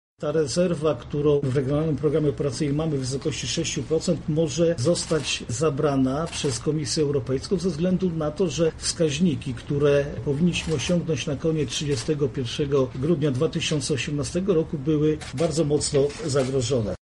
-Jeśli wszystkie gałęzie programu będą skutecznie realizowane jesteśmy w stanie zachować jego część– mówi Marszałek Województwa Lubelskiego Jarosław Stawiarski.